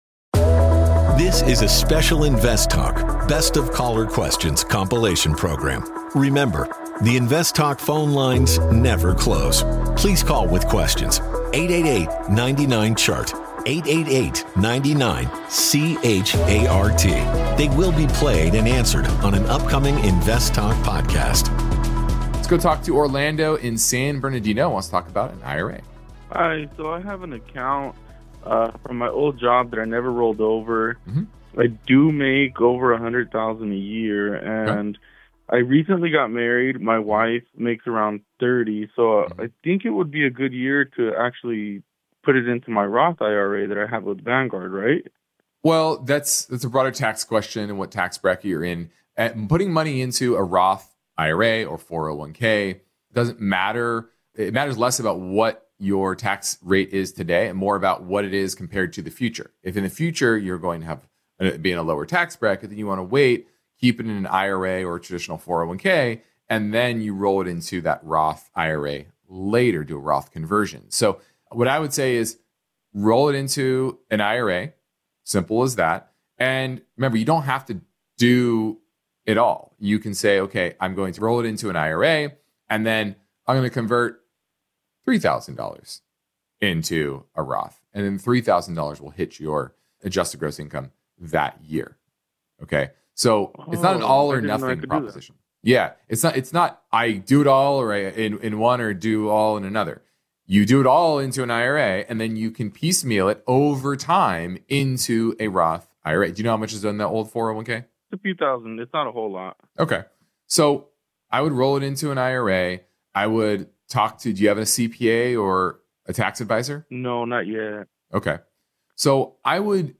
Thanksgiving Day Special - Best of Caller Questions